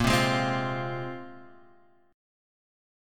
A#7sus2 chord